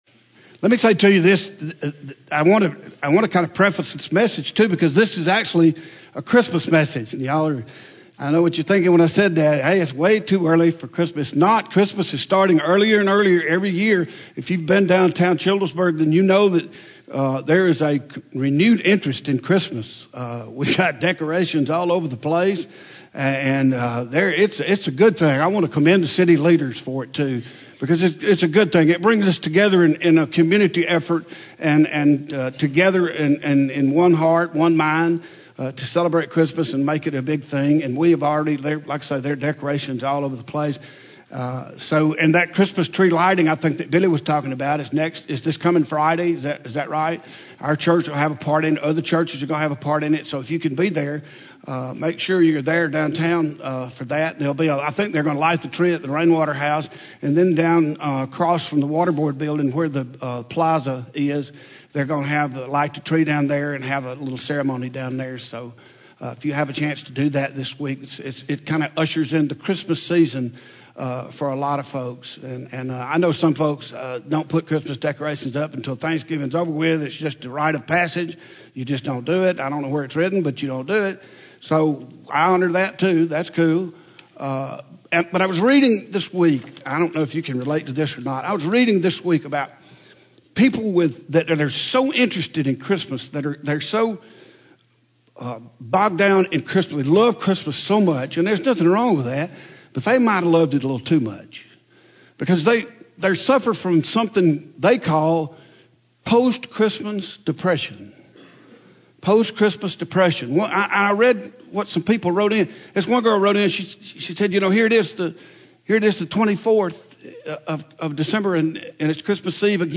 Home › Sermons › Abundant Living